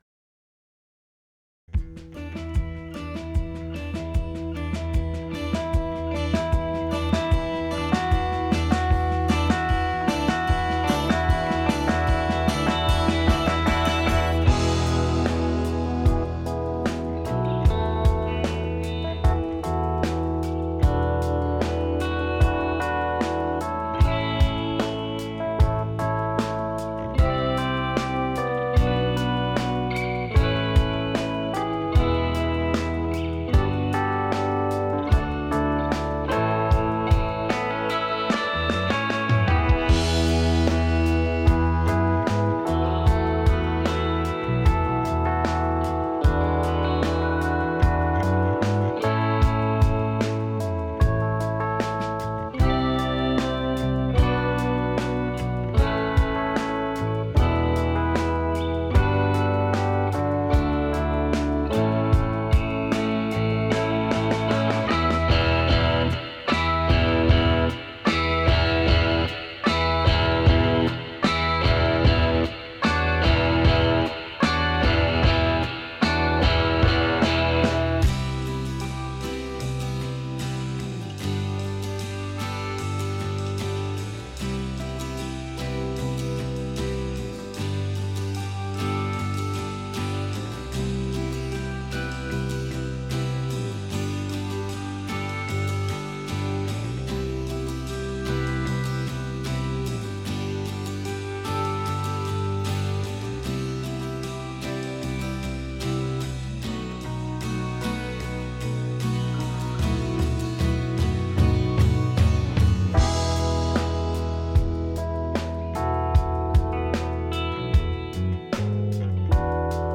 But here's a recent recording I made with the 246. It's not finished yet -- it's just lacking vocals -- but this is already with two external bounces (to and from the computer). So this is a two-track submix now that's on tracks 1 and 2, and the vocals will go on tracks 1 and 2. To me, this sounds as "clean" as any digital recording I hear on this place (or cleaner, to be honest), yet it still sounds warm and pleasant (to me, anyway).